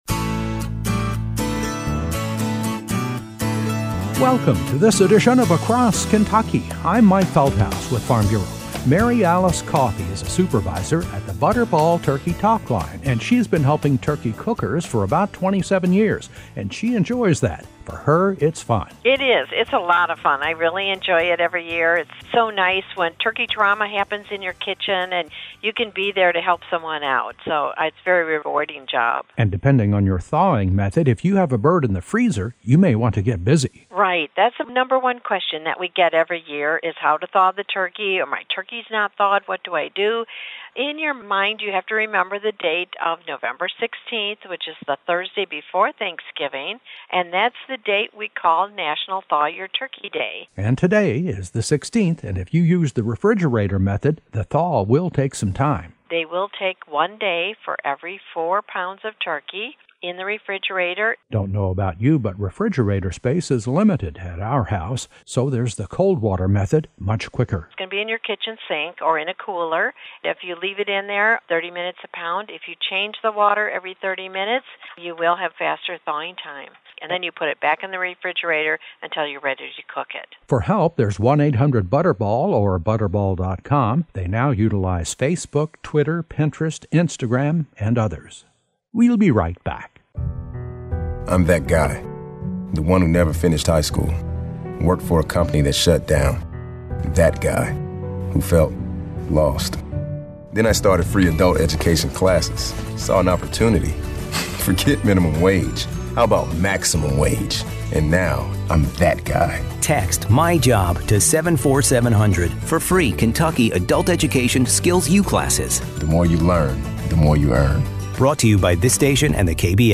A feature report